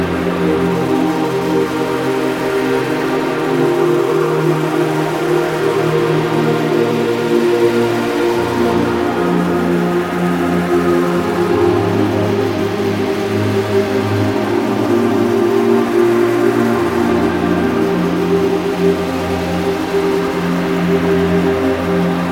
描述：就像撒哈拉沙漠中呼啸而过的沙浪。
Tag: 86 bpm Ambient Loops Pad Loops 3.76 MB wav Key : Unknown